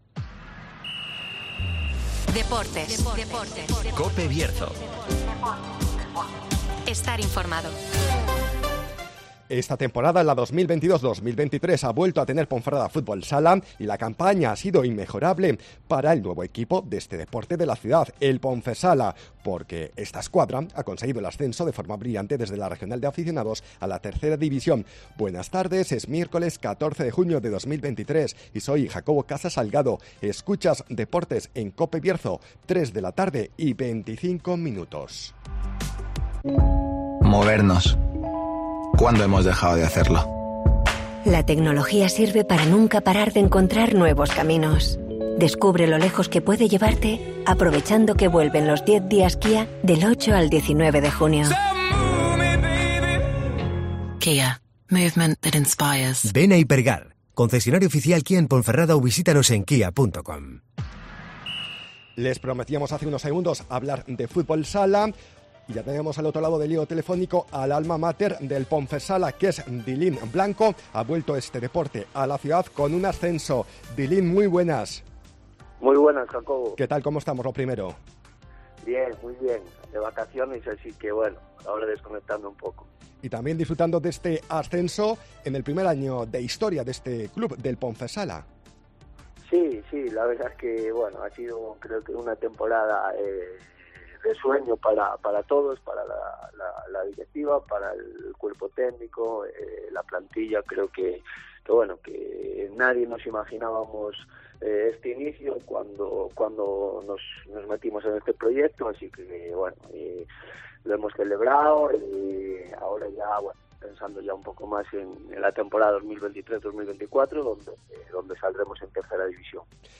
DEPORTES